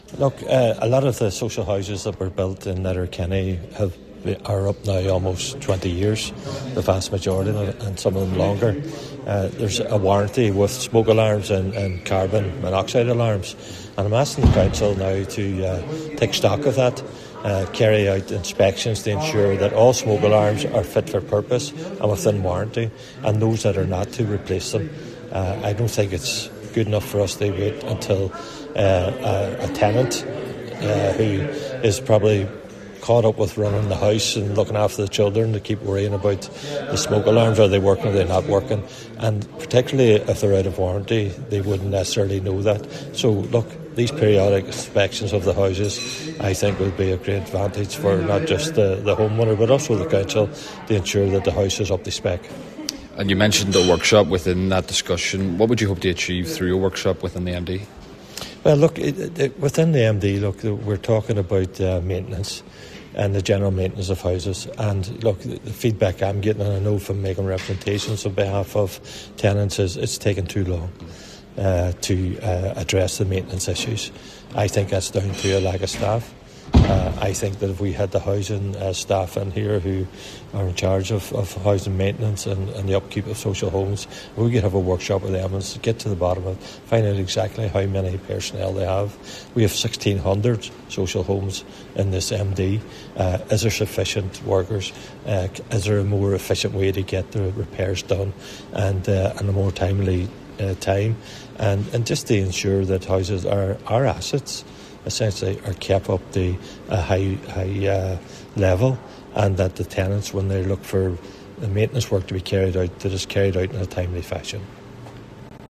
A recent meeting of Letterkenny Milford Municipal District heard of issues surrounding smoke and carbon monoxide alarms in social housing.
This was raised by Cllr Gerry McMonagle, who has called for the council to do a full audit of all the alarms fitted in council-owned properties.